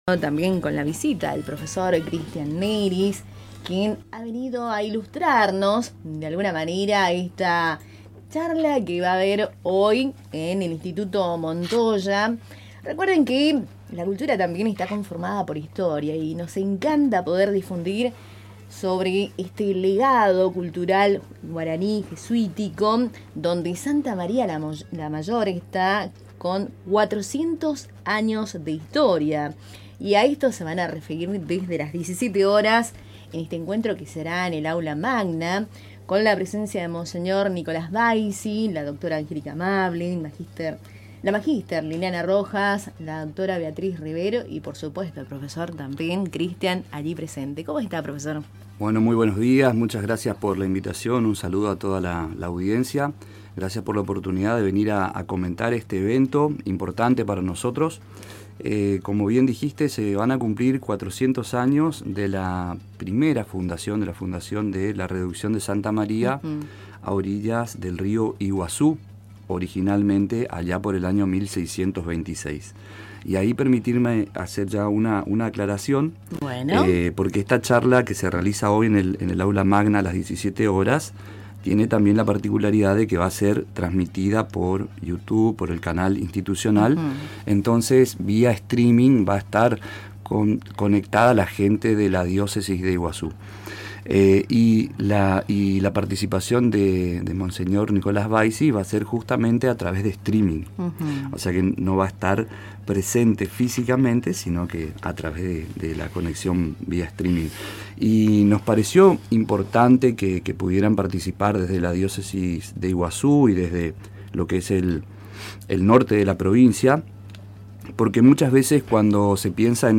Escuchá la entrevista realizada en Radio Tupambaé: https